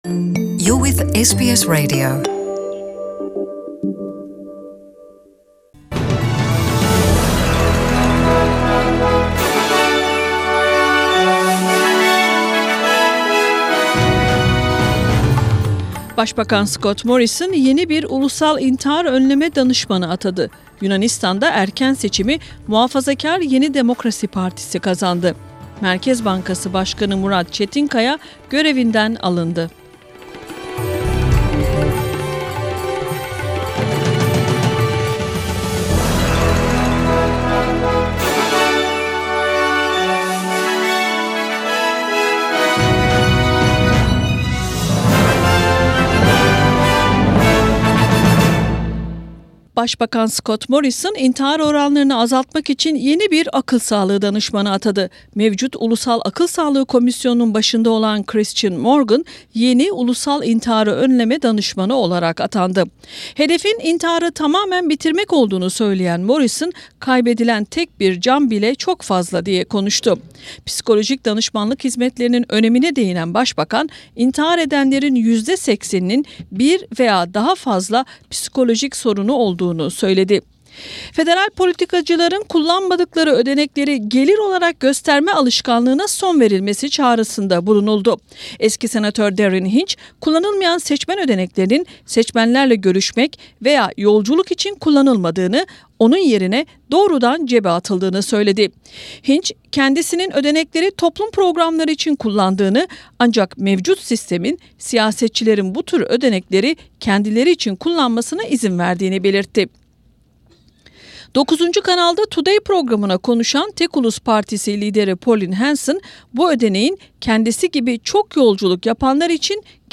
SBS Radio Turkish Program presents news from Australia, Turkey and the world.